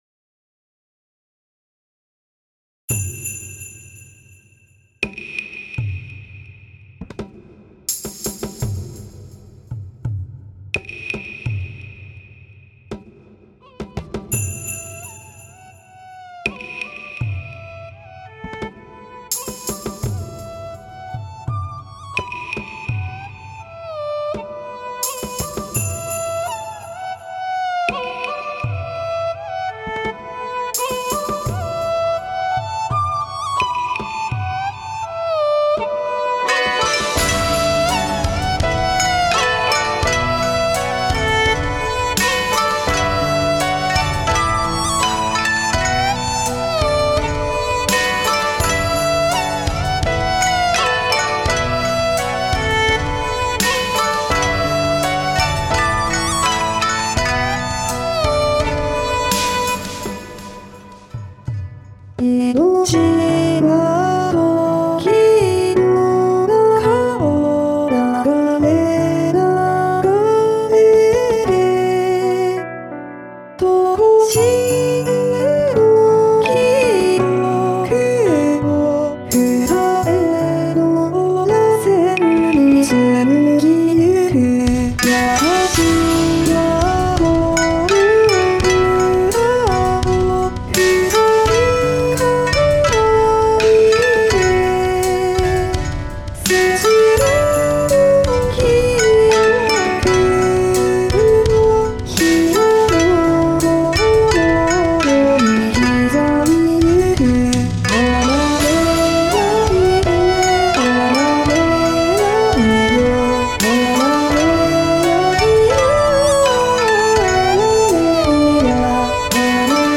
今回はg-1で歌わせてますが。